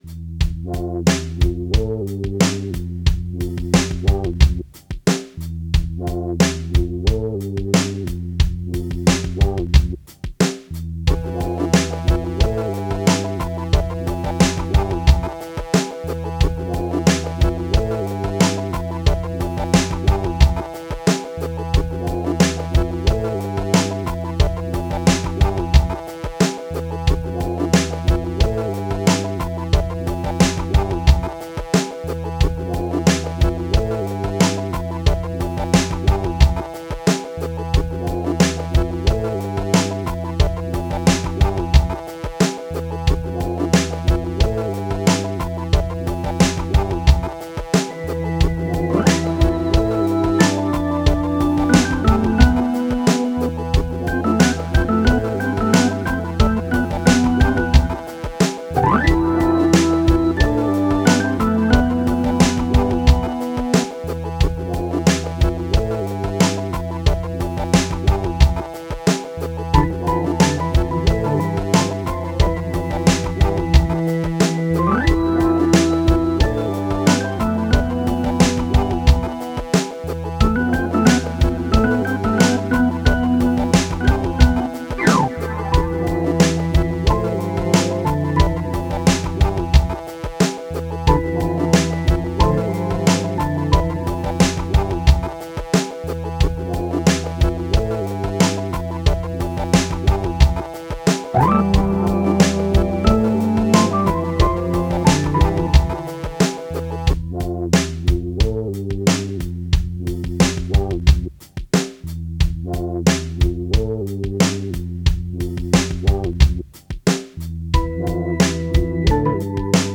Spacy Hiphop, Resonator Guitar, Organ.